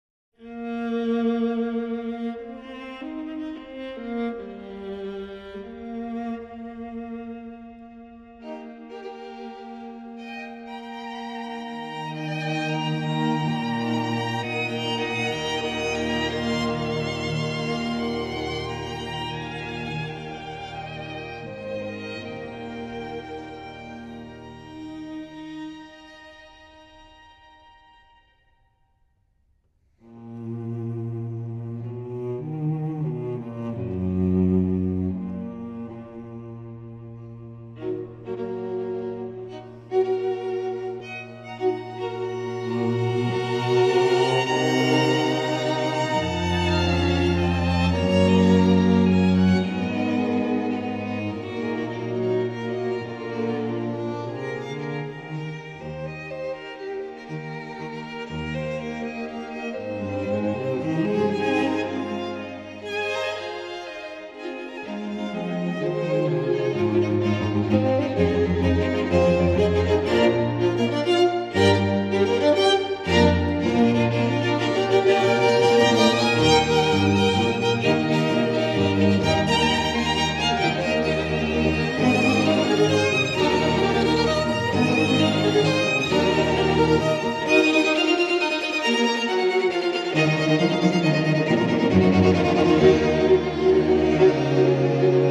[弦樂五重奏,作品97、弦樂六重奏,作品48]
[String Quintet,Op.97、String Sextet,Op.48]
古典音樂